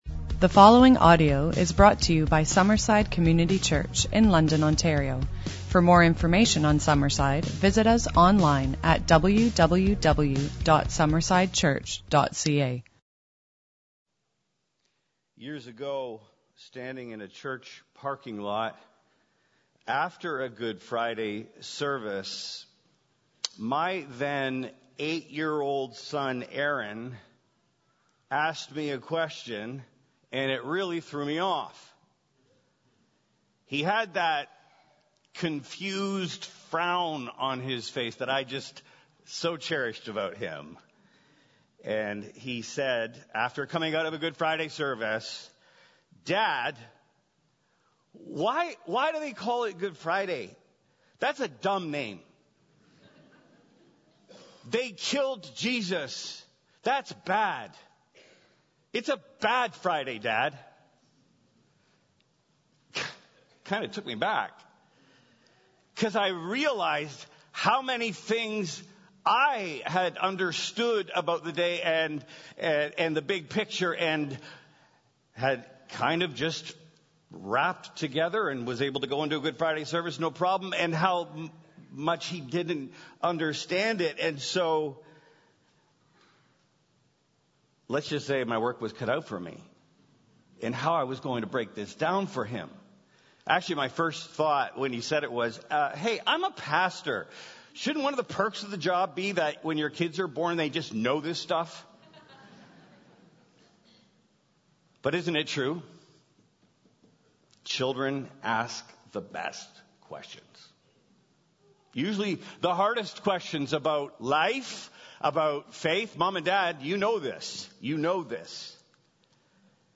GOOD Friday Service